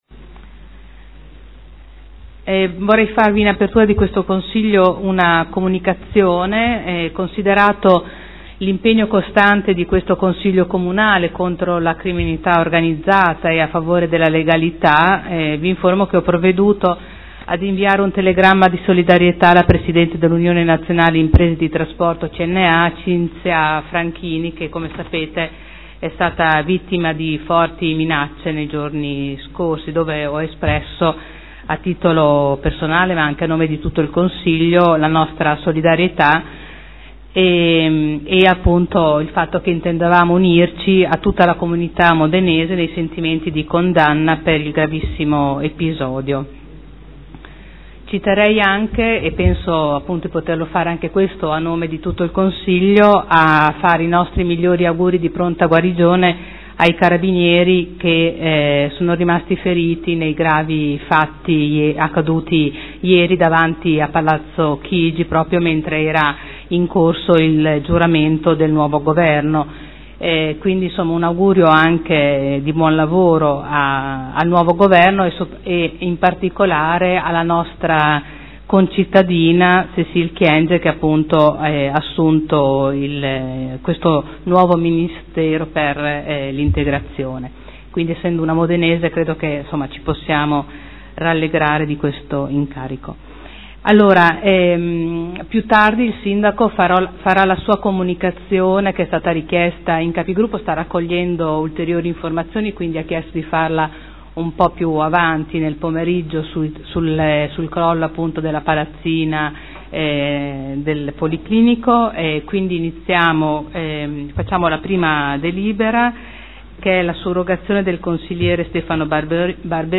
Seduta del 29/04/2013 La Presidente Caterina Liotti apre i lavori del Consiglio.